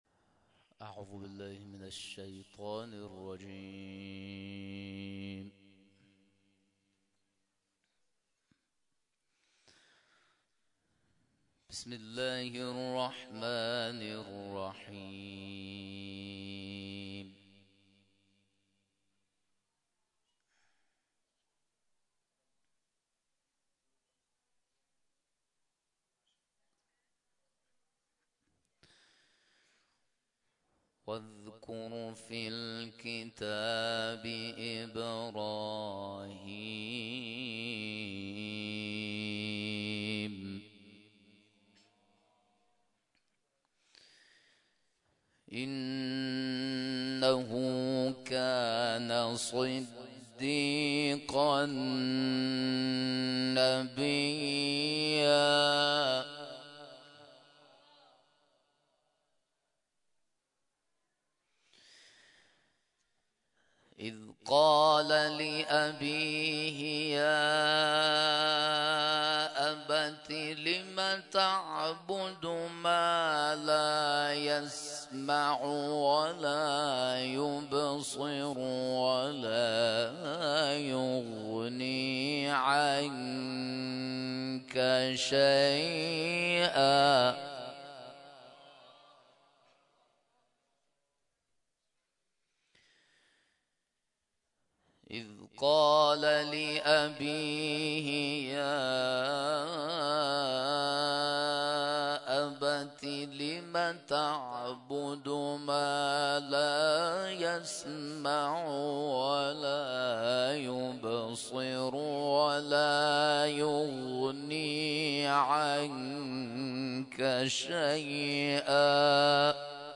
تلاوت
در مسجد 14 معصوم خانی آباد برگزار شد